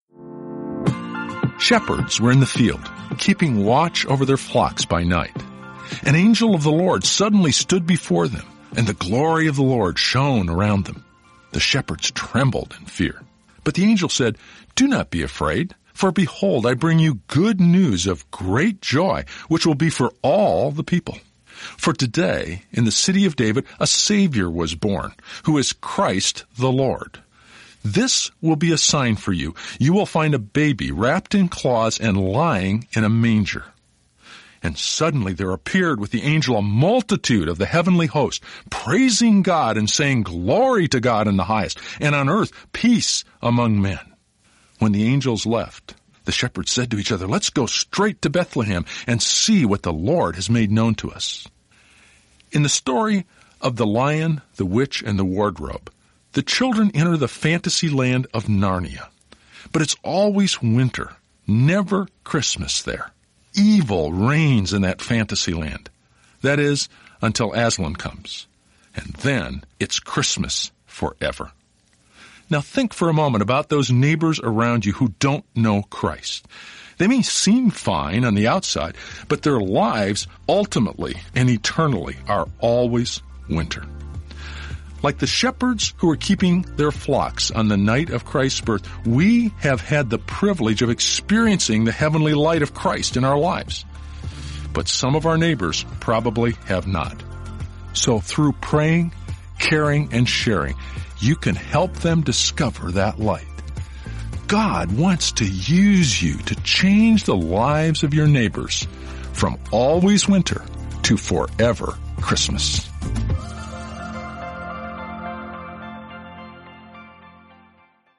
Each day contains a short (1–2 minute) audio message that ties into the journey theme.